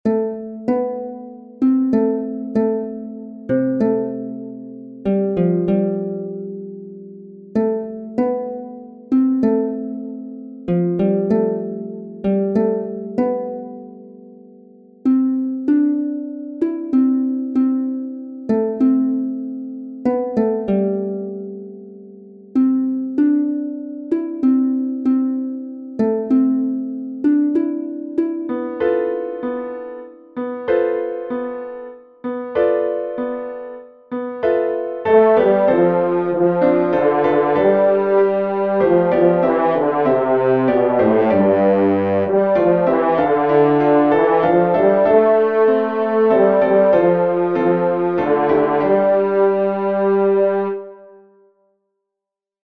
Voice part practice (top of page 4 to the top of page 7):
BASS
dream_isaiah_saw-bass.mp3